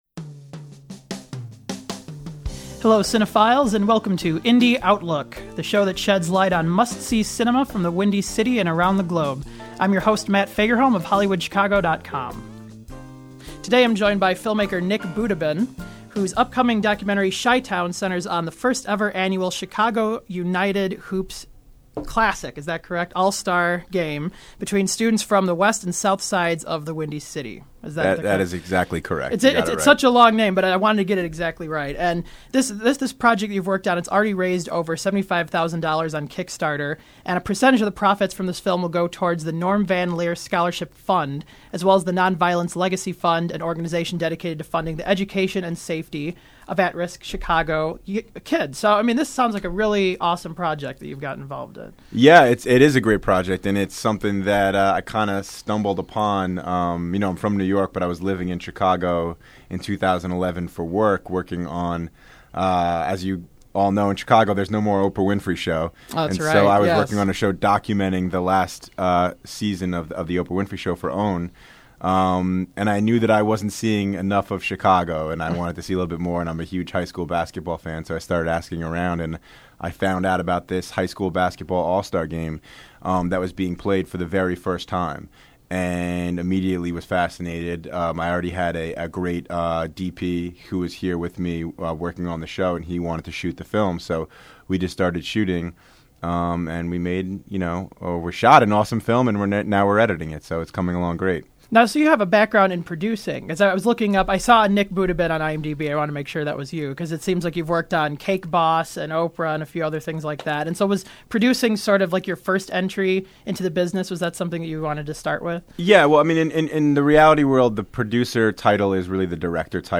The program was produced at Columbia College Chicago.